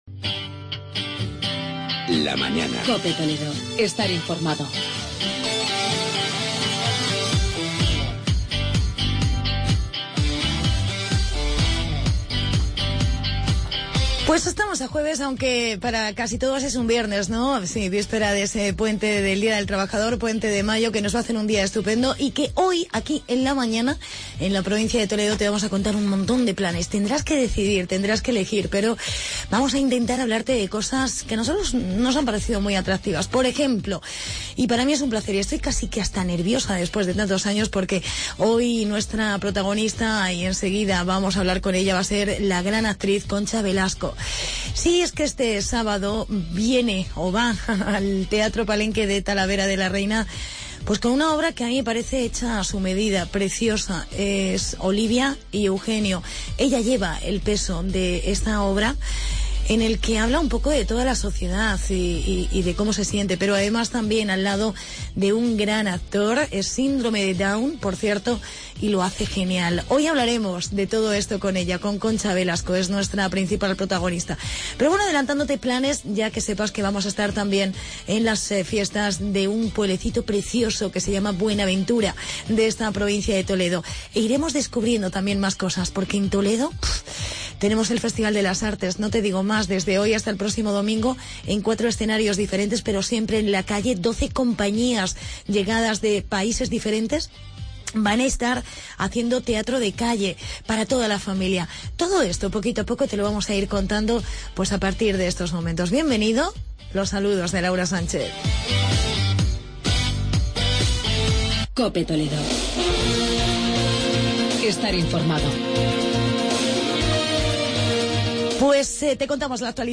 Entrevistamos a la actriz Concha Velasco por su obra "Olivia y Eugenio" y hablamos con el alcalde de Buenaventura,...